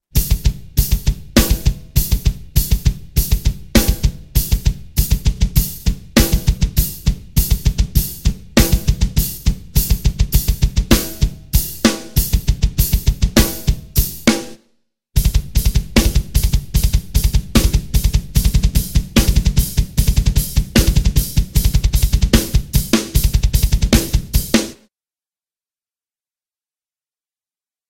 Recueil pour Batterie